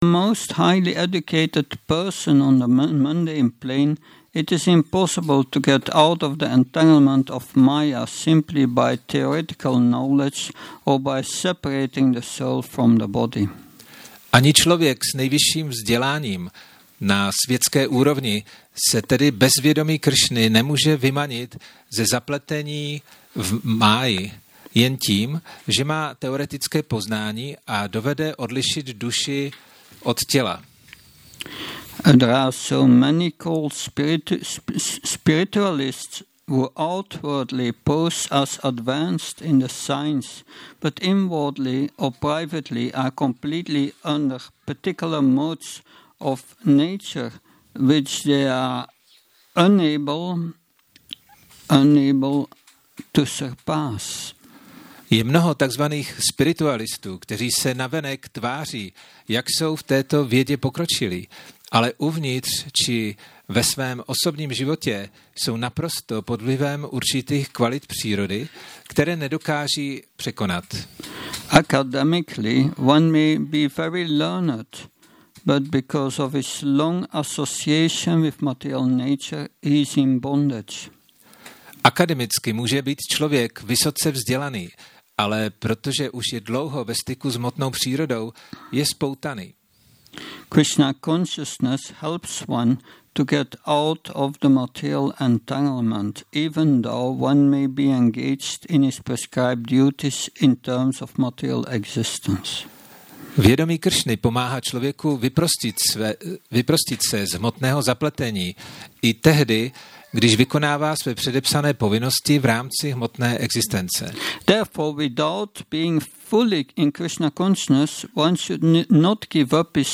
Přednáška BG-3.33 – Šrí Šrí Nitái Navadvípačandra mandir